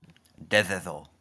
Dededo (Chamorro: Dedidu; Spanish: Spanish pronunciation: [deðeðo]